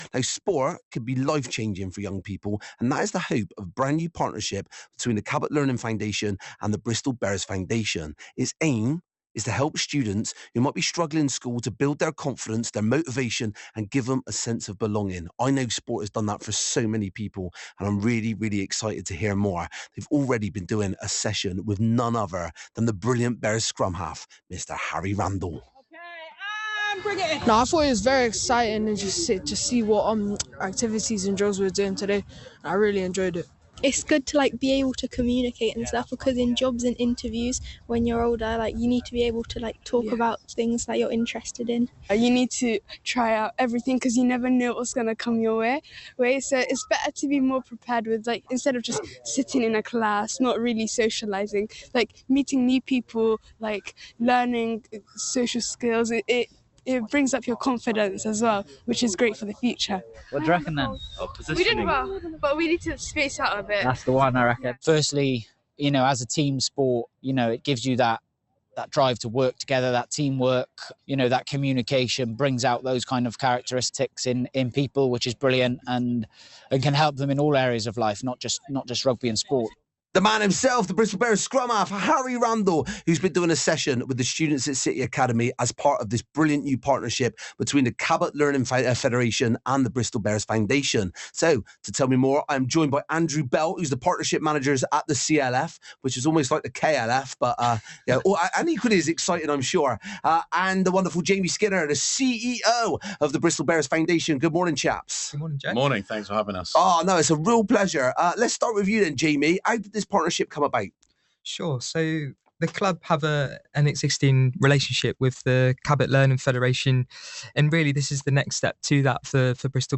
interviewed by BBC Radio Bristol